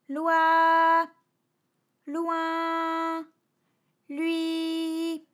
ALYS-DB-001-FRA - First, previously private, UTAU French vocal library of ALYS
loi_loin_lui.wav